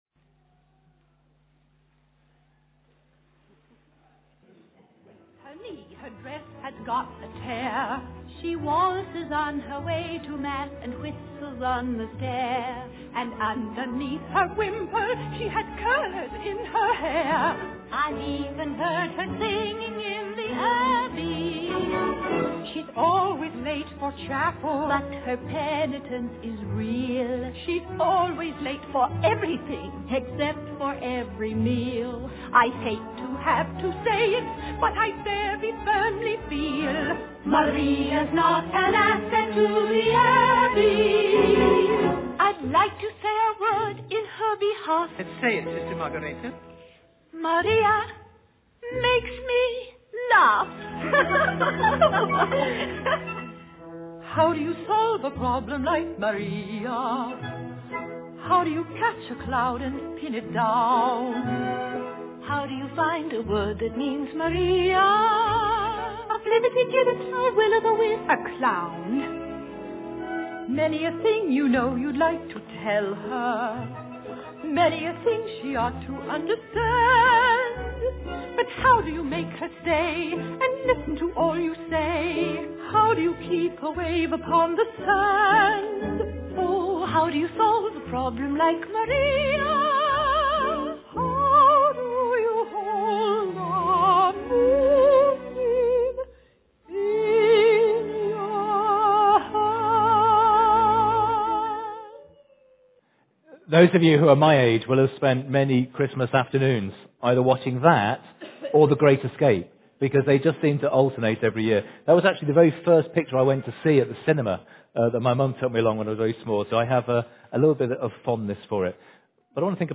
Theme – Carol Service
Genre: Speech.